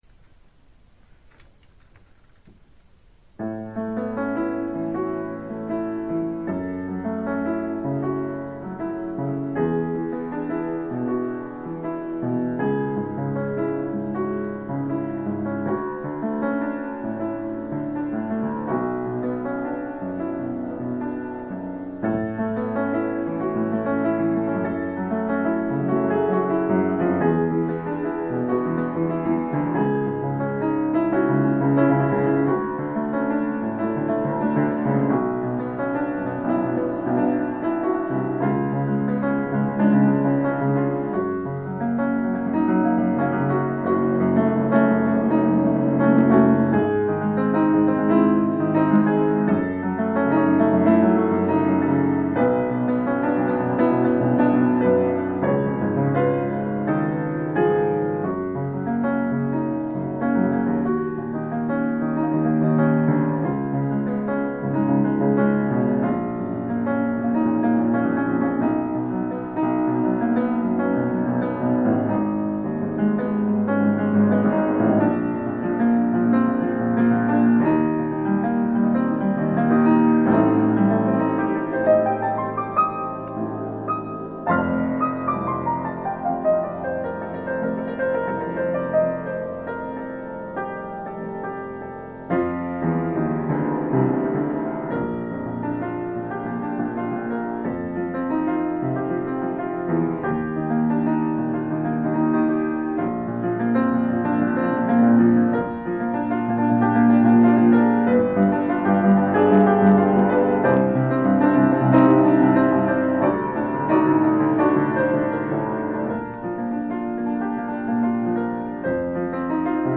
מצטער על ההקלטה הלא הכי המהוקצעת ומושלמת.
הנגינה זורמת ולא רעה בכלל, אבל נהרסת בגלל איכות הקלטה בעייתית.
אהבתי.. מאוד מרגיע ונוגע..
מה שהפריע לי זה שהקצב משתנה כל כמה רגעים.